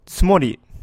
Also, just for reference, here’s the pronunciation of つもり.